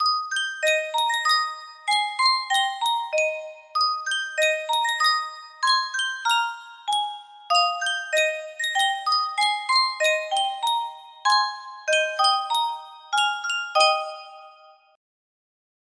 Yunsheng Music Box - Kumbaya 5872 music box melody
Full range 60